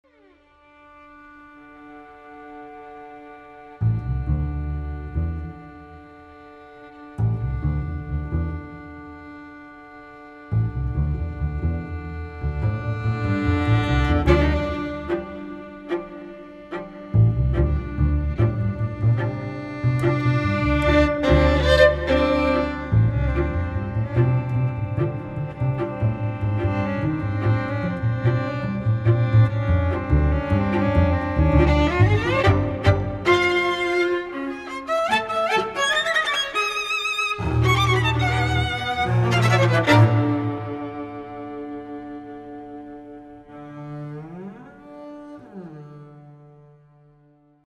Please note: These samples are not of CD quality.
Trio for Violin, Viola and Bass